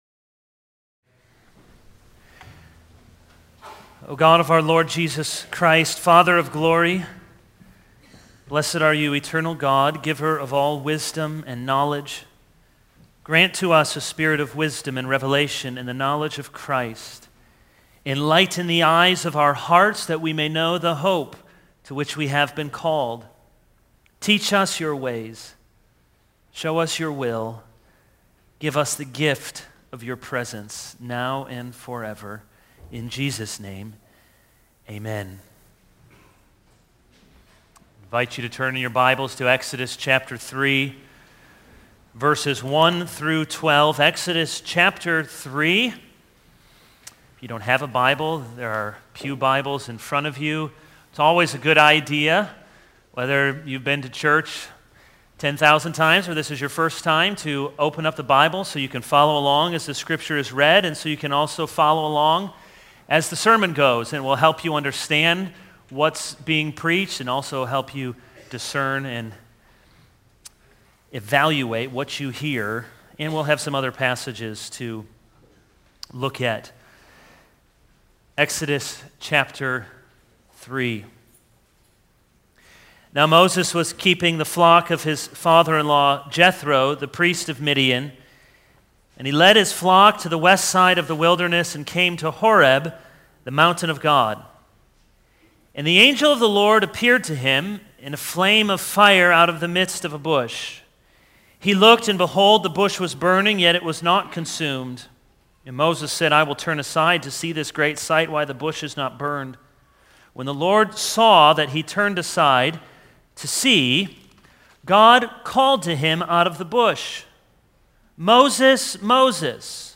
This is a sermon on Exodus 3:1-12.